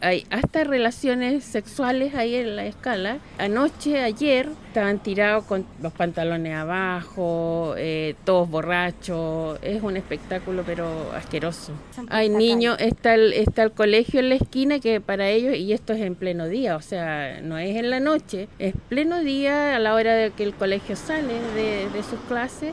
Así lo comentó una vecina, quien enfatizó en lo angustiante que les resulta presenciar estas acciones.